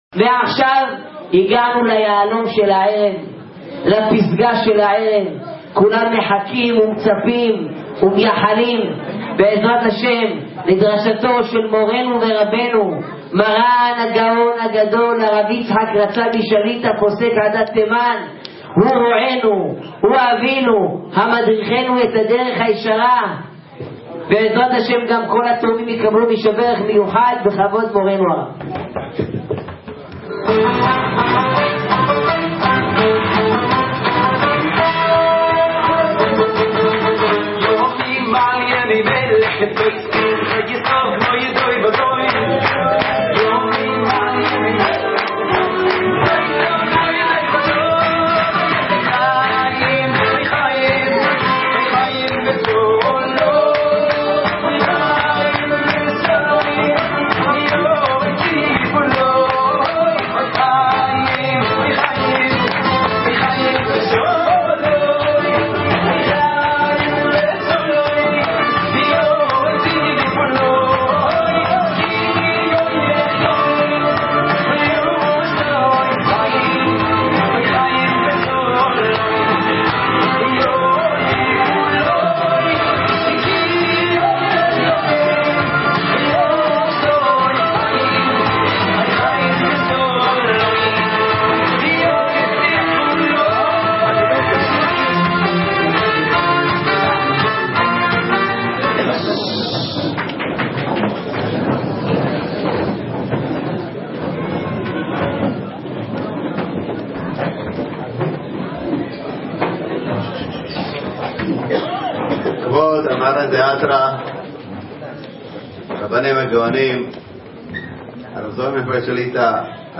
חדש! דרשת מרן שליט"א בחול המועד פסח ה'תשע"ט - רכסים ת"ו